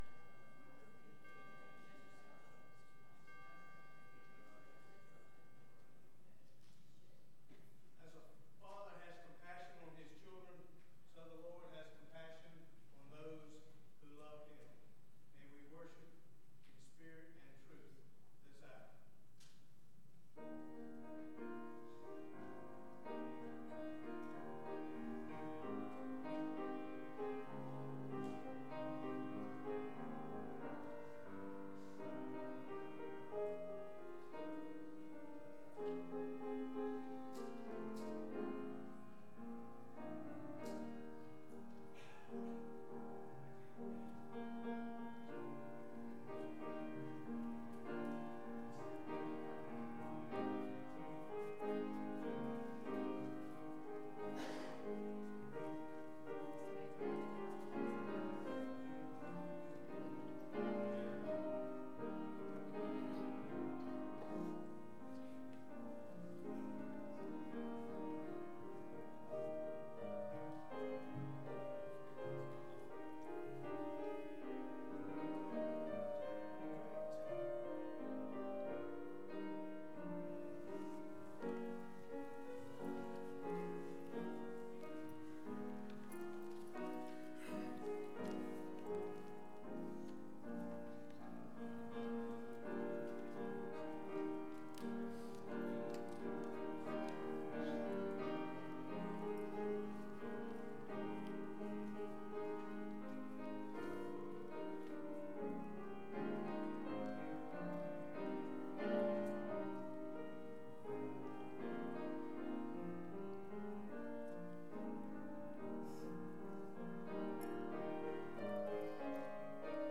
Acts 2:14-41 Service Type: Morning Bible Text